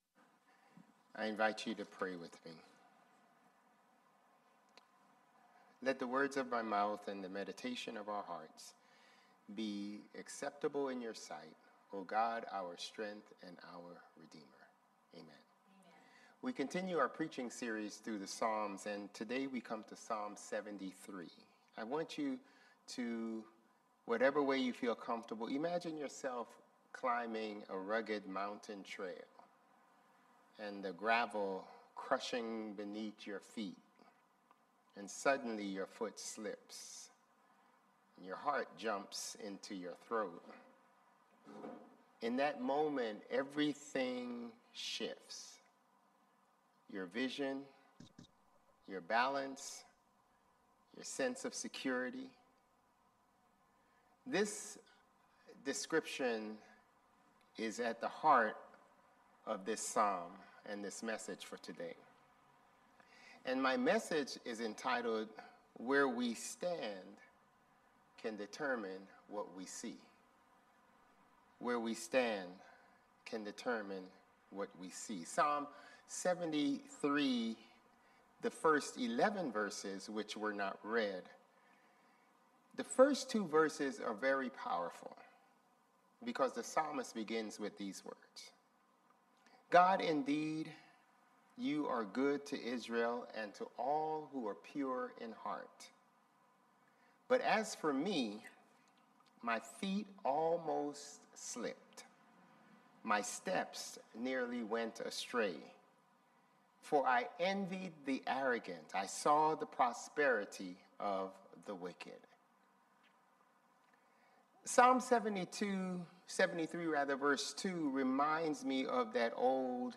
Sermons | Bethel Lutheran Church